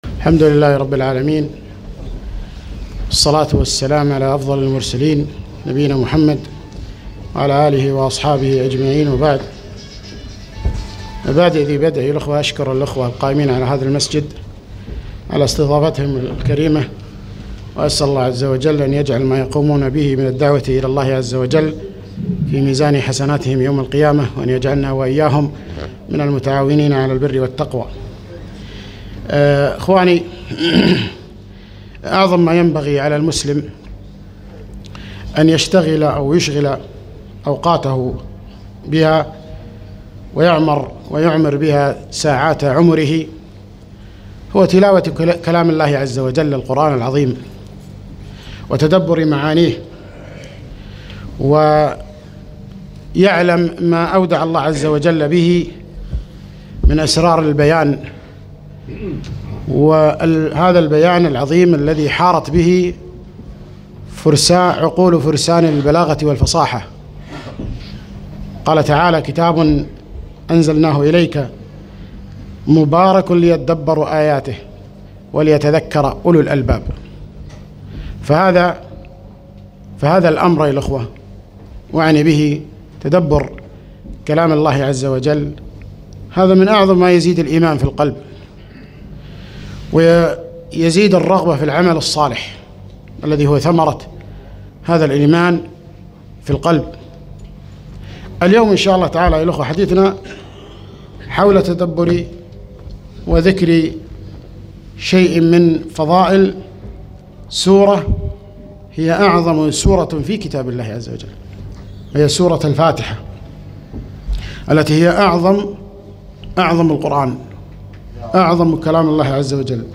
محاضرة - نظرات مع سورة الفاتحة